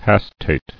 [has·tate]